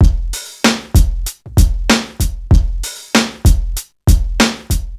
• 96 Bpm Drum Groove F Key.wav
Free drum groove - kick tuned to the F note.
96-bpm-drum-groove-f-key-lK2.wav